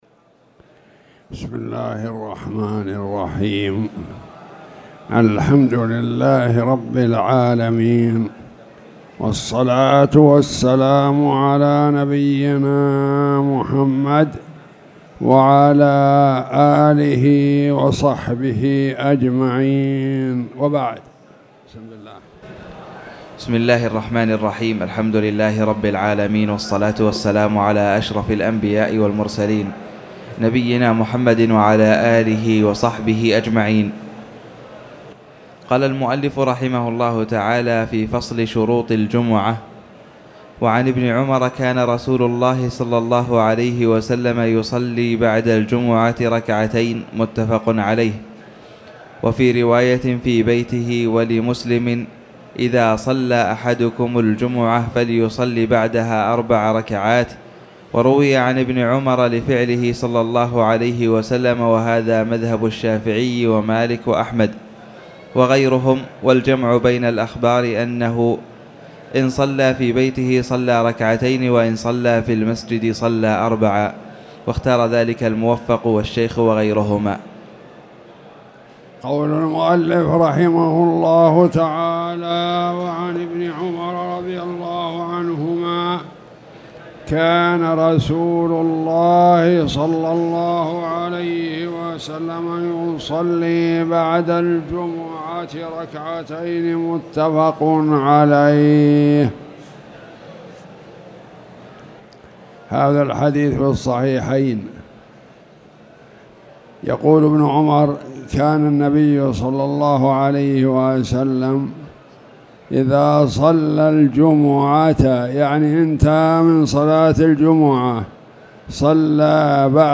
تاريخ النشر ٧ جمادى الأولى ١٤٣٨ هـ المكان: المسجد الحرام الشيخ